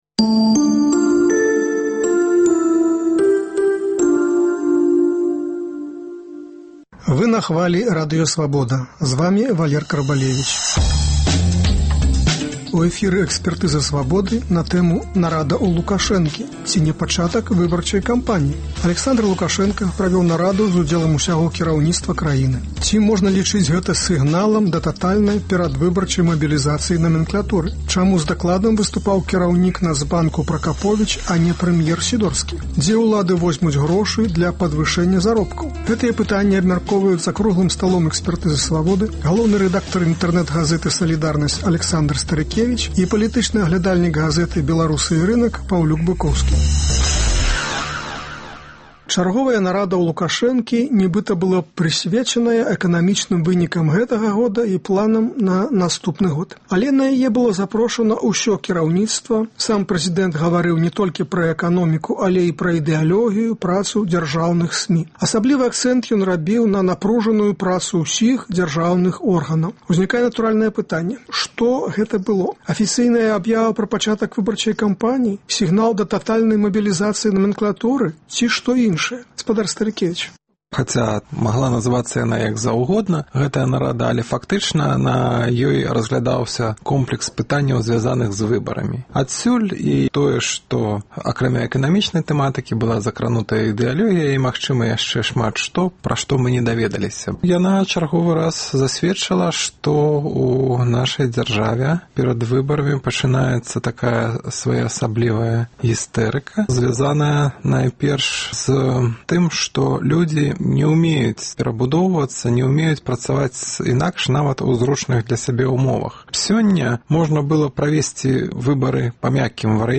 Дзе ўлады возьмуць грошы для падвышэньня заробкаў? Гэтыя пытаньні абмяркоўваюць за круглым сталом